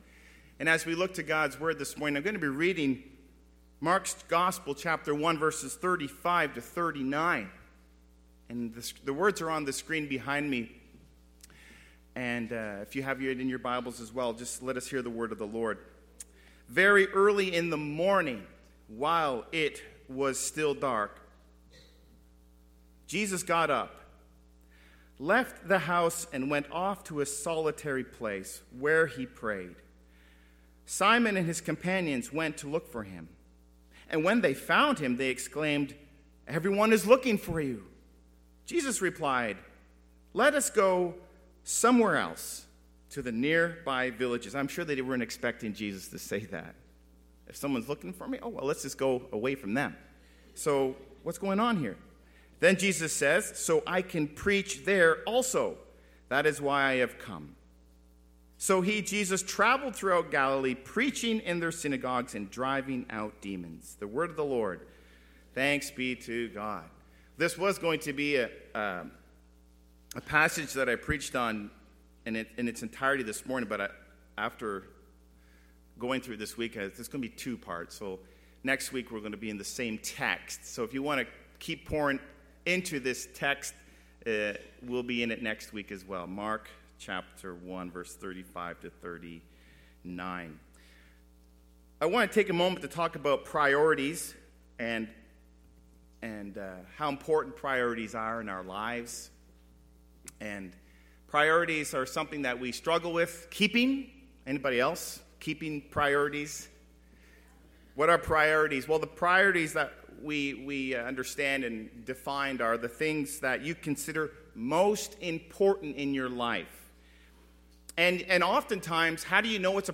Audio Sermons - Campbellford Baptist Church Inc.